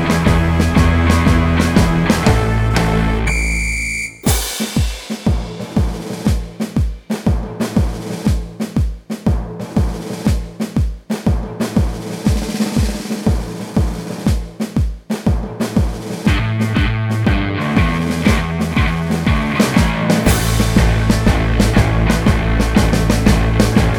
no Backing Vocals Comedy/Novelty 3:41 Buy £1.50